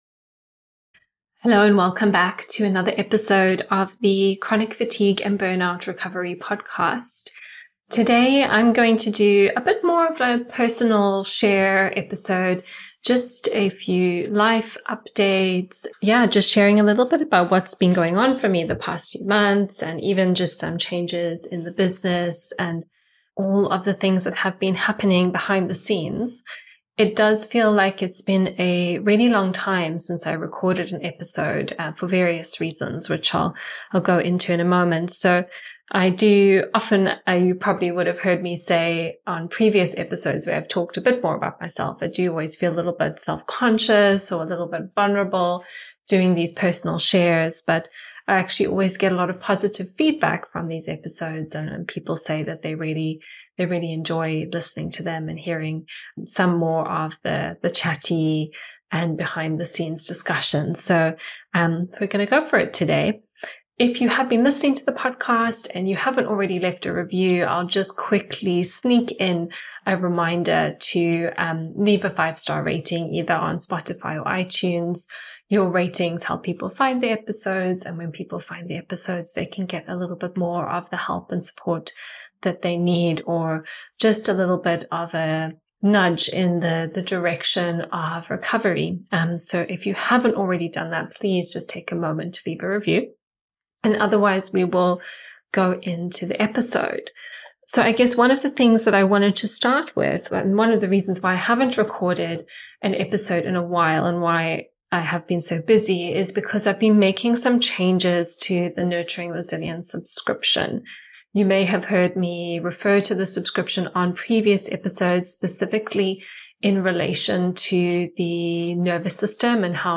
This is a chatty "behind the scenes” episode